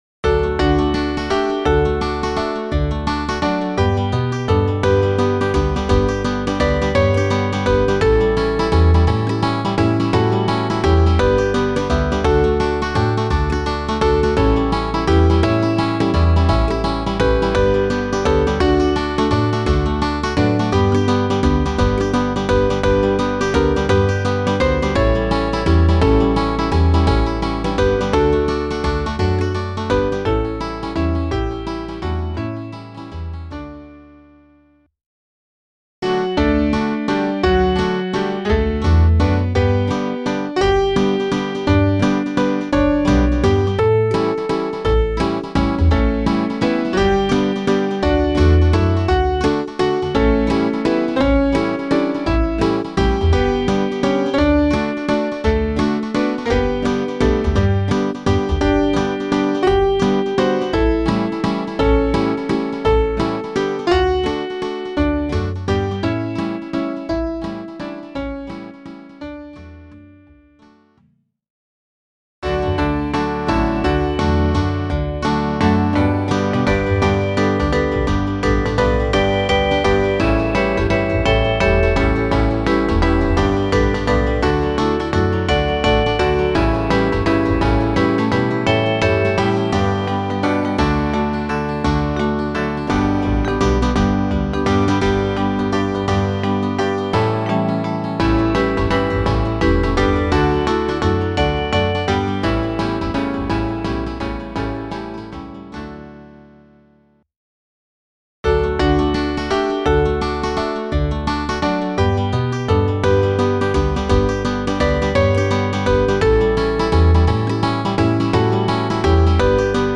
Die Hörprobe stammt von einer Studioaufnahme aus dem Jahr 2019.
Variationen